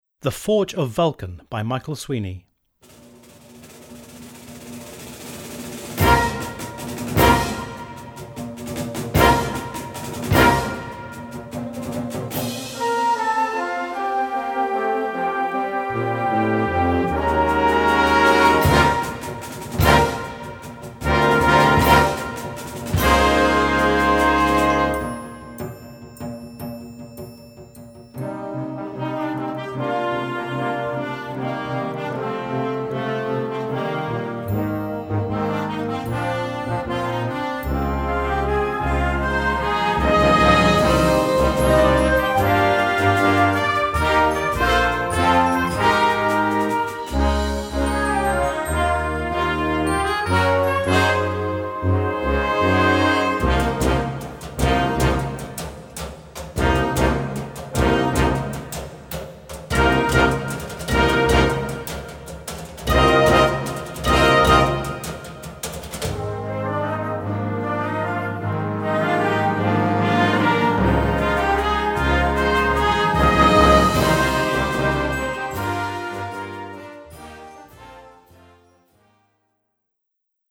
Flex Band (5-stimmig)
Besetzung: Blasorchester